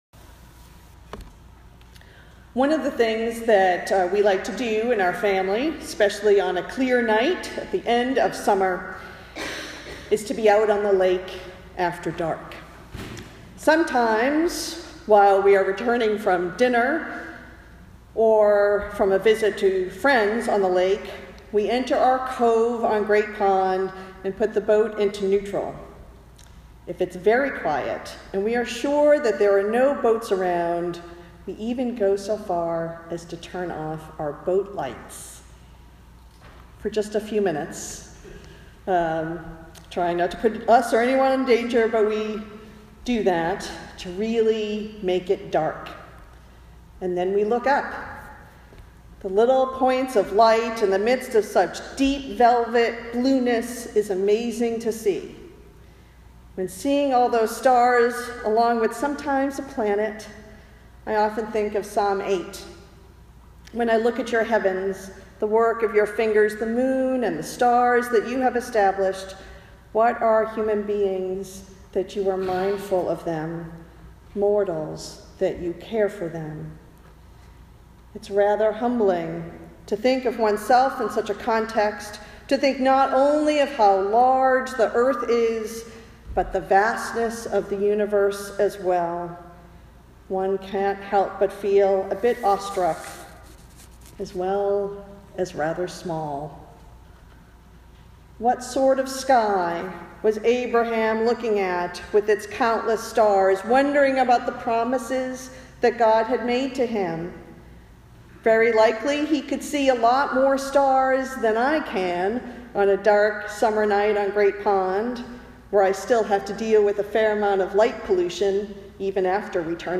Related Posted in Sermons (not recent)